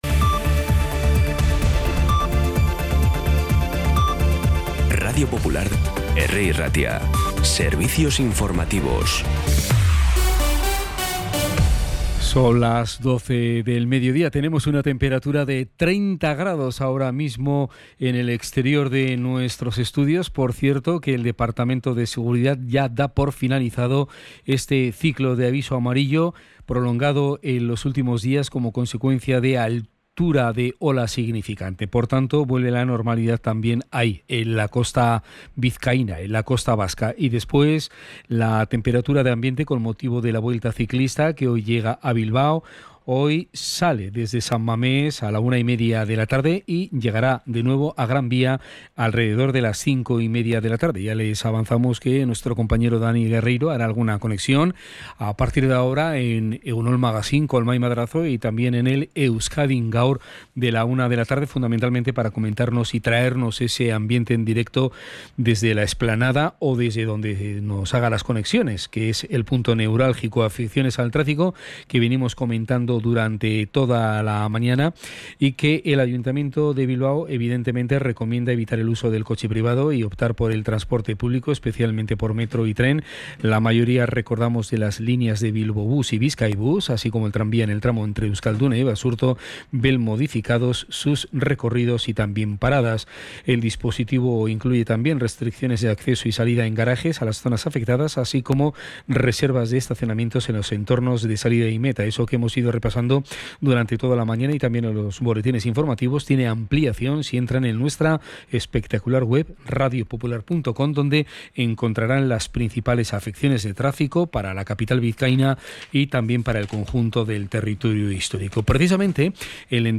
Las noticias de Bilbao y Bizkaia del 3 de septiembre a las 12
Los titulares actualizados con las voces del día.